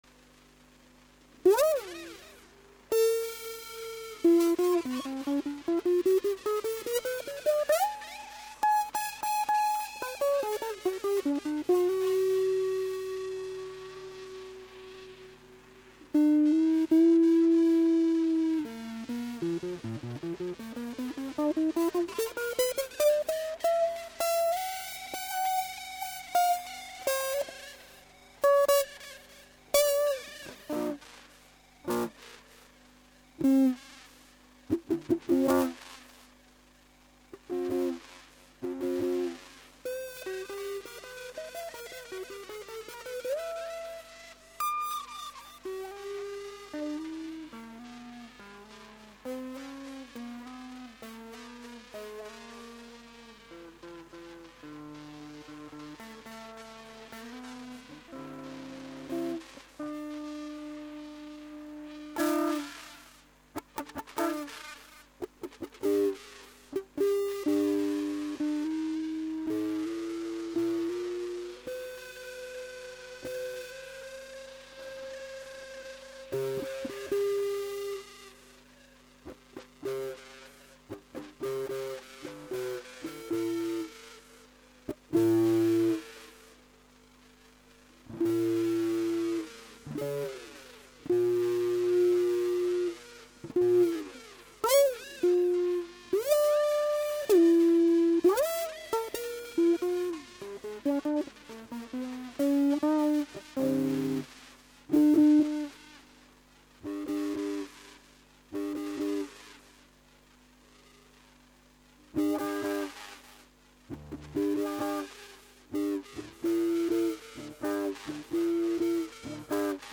音ヨレんし最高速からの リバースノイズも少ないし